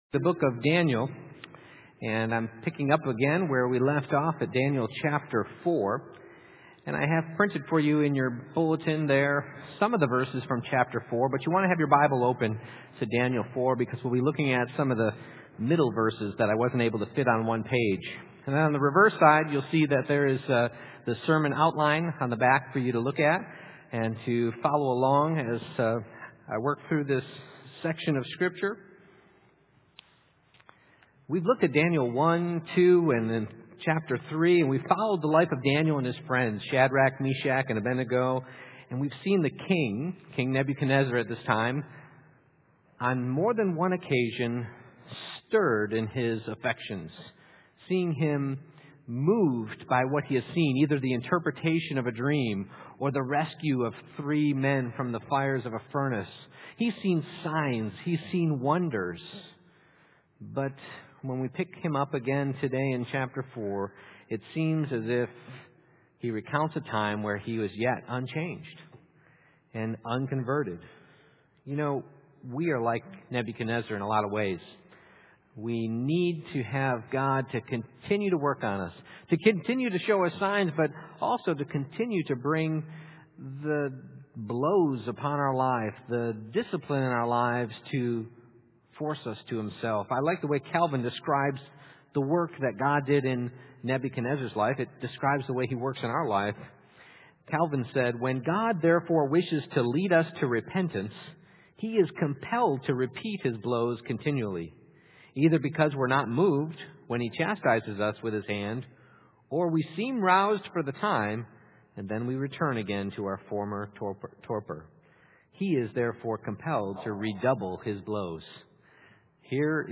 Exposition of Daniel Passage: Daniel 4:1-37 Service Type: Morning Worship « Jesus did What?